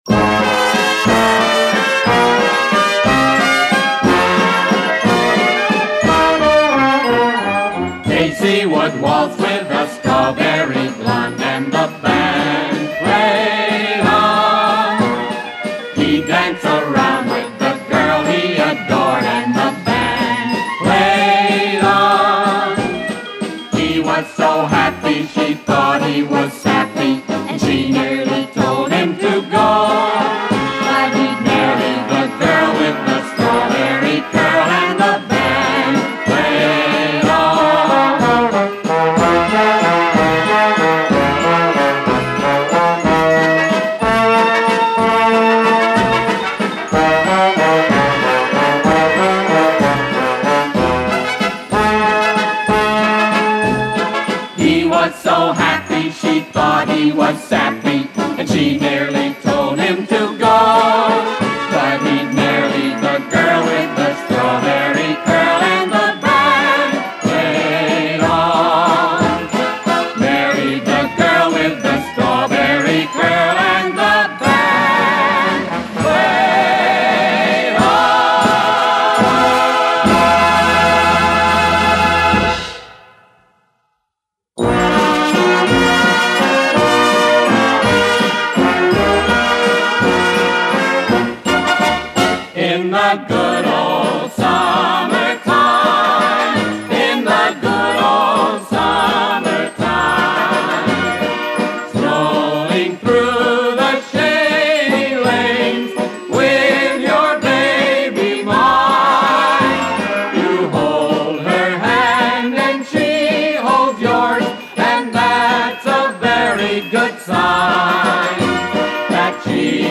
This is actually a compilaton of former 78 singles
most are performed by singer
children's discs